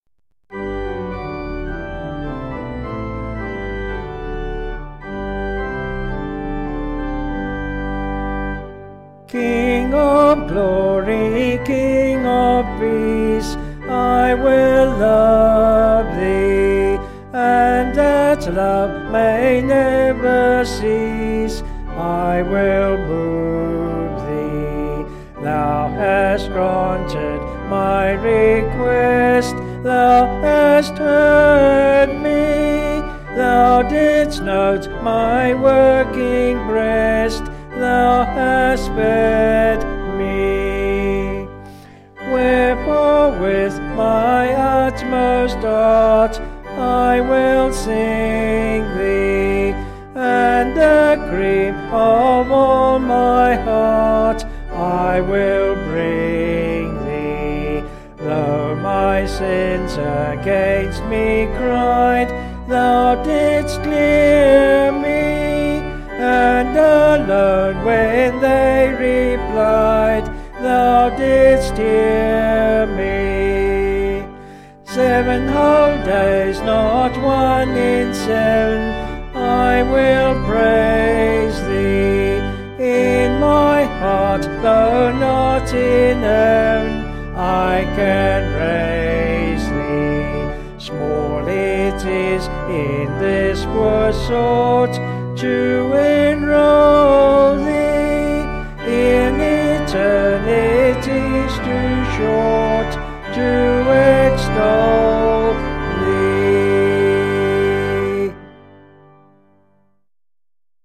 Vocals and Organ   263.3kb Sung Lyrics